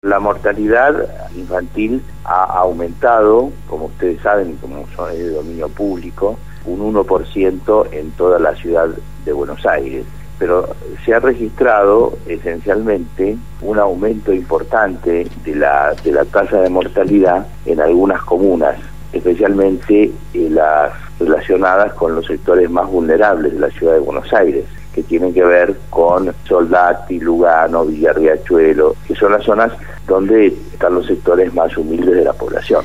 El Doctor Jorge Selser, legislador por la Ciudad de Buenos Aires de Proyecto Sur y titular de la Comisión de Salud, habló en «Punto de Partida» (Lunes a Jueves, de 08:00 a 10:00 hs) refiriéndose al aumento de la Mortalidad infantil en la Ciudad de Buenos Aires.